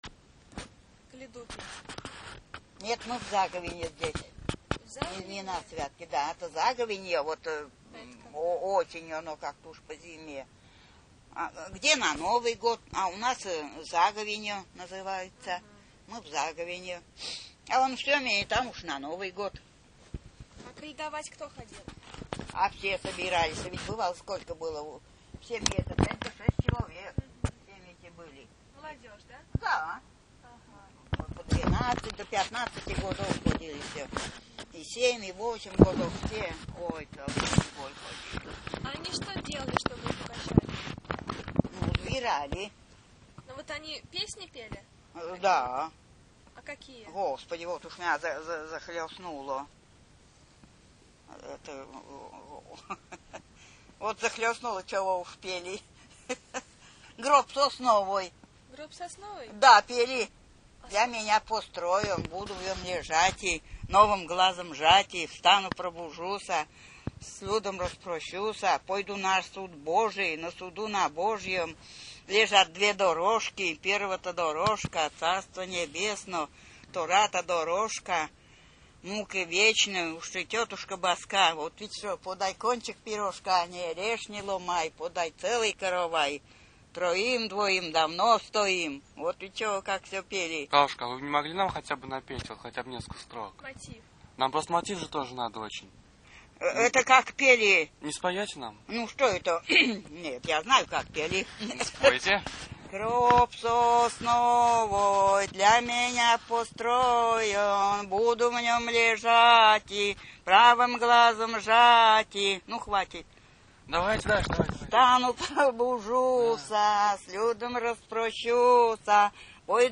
Рассказ